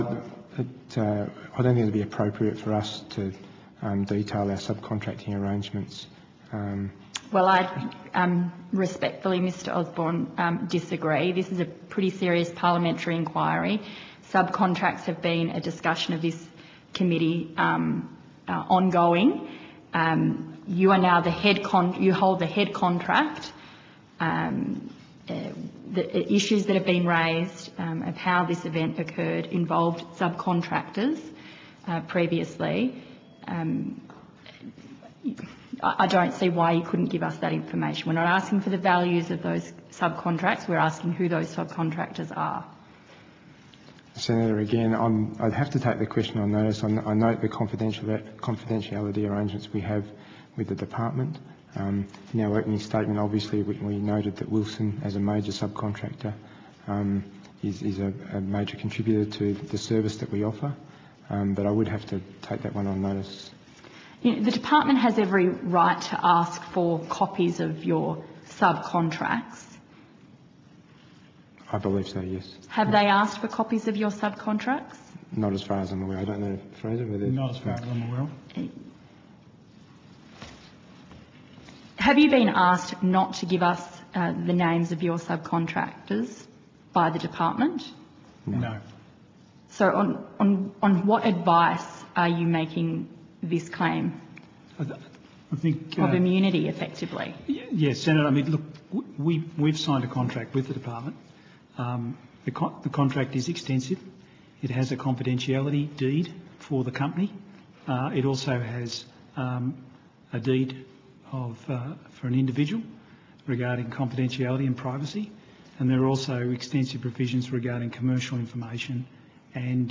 Transfield gives evidence at the Manus Island inquiry that the names of their subcontractors is confidential.